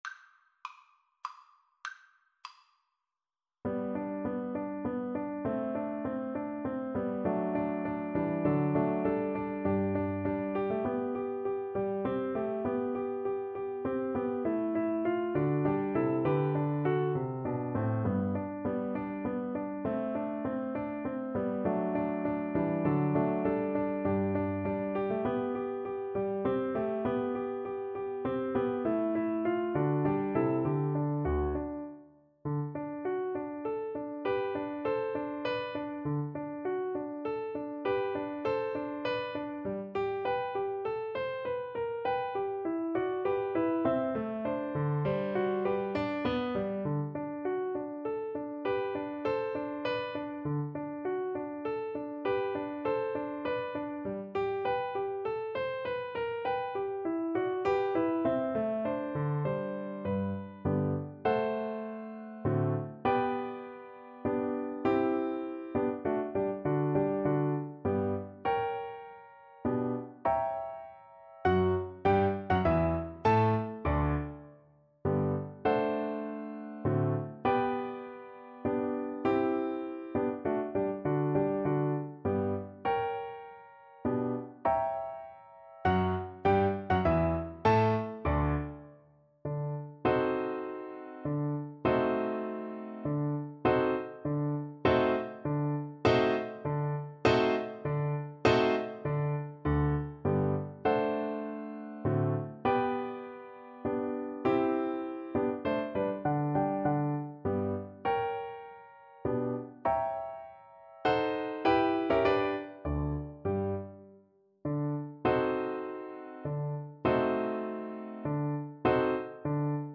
3/4 (View more 3/4 Music)
Menuetto Moderato e grazioso
Classical (View more Classical Cello Music)